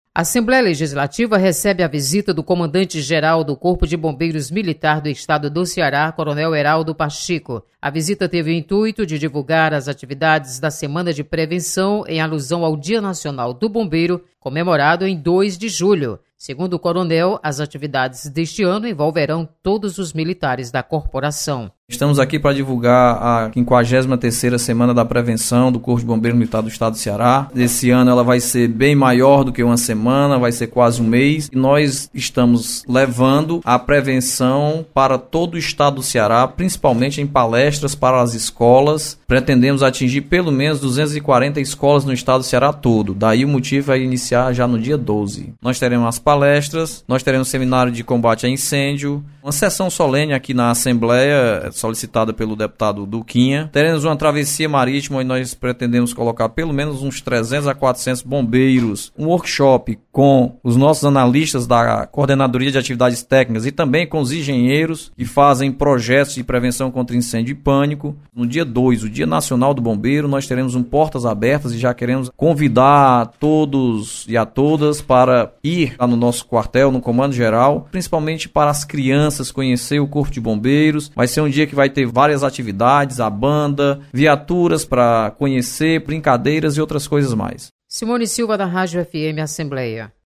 Comandante do Corpo de Bombeiros visita Assembleia Legislativa.  Repórter